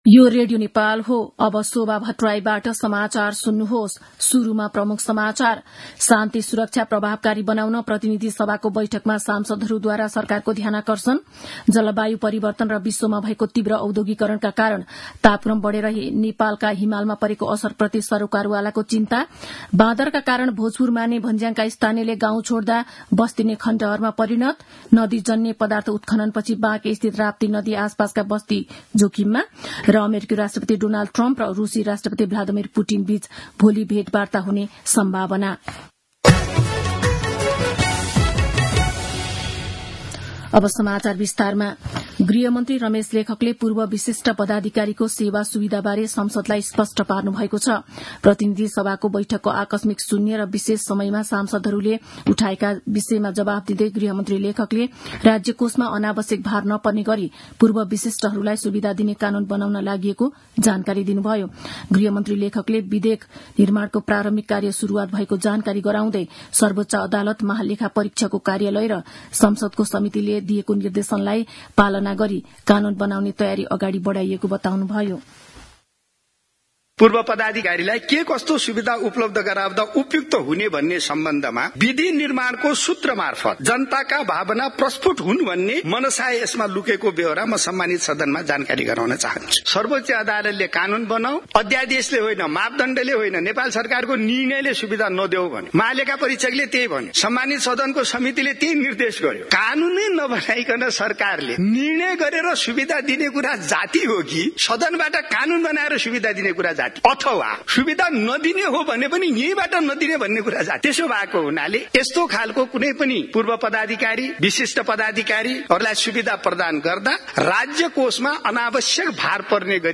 दिउँसो ३ बजेको नेपाली समाचार : ४ चैत , २०८१
3-pm-news-2.mp3